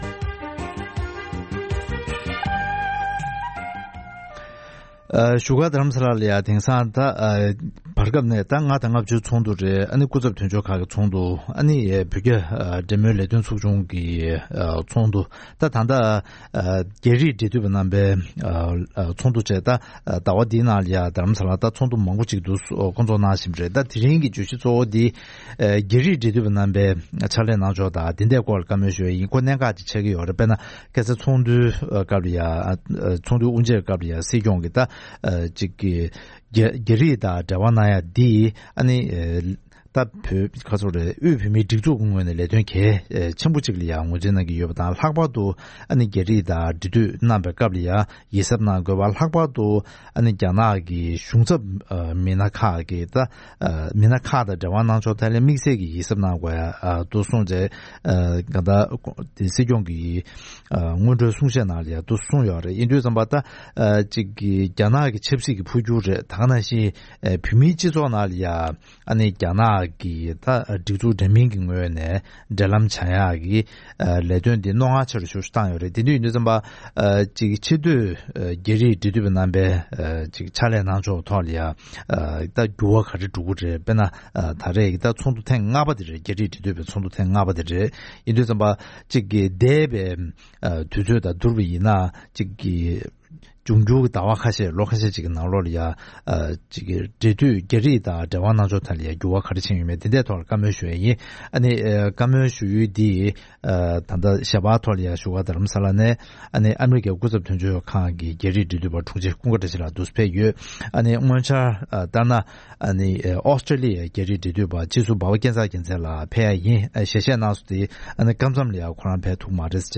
རྒྱ་རིགས་འབྲེལ་མཐུད་ཀྱི་ལས་དོན་སྤེལ་ཕྱོགས་སོགས་ཀྱི་གནད་དོན་དང་འབྲེལ་བའི་སྐོར་གླེང་མོལ།